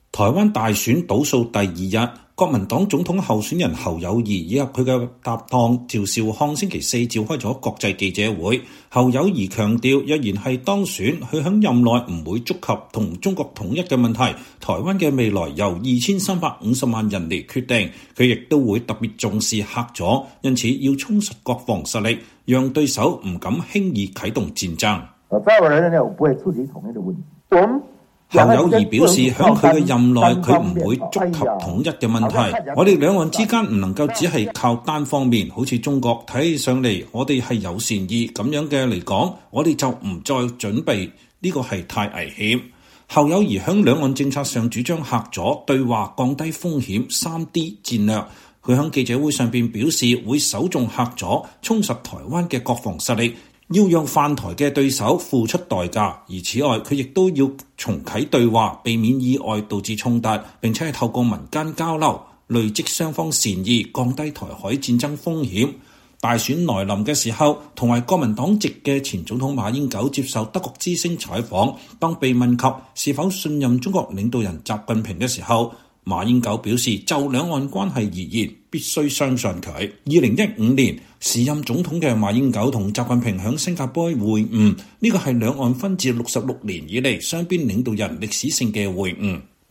台灣大選倒數第二天，國民黨總統候選人侯友宜及其搭檔趙少康週四召開國際記者會。侯友宜強調，若是當選，在任內不會觸及和中國統一的問題，台灣的未來由2350萬人來決定；他也會特別重視“嚇阻”，因此要充實國防實力，讓對手不敢輕易啟動戰爭。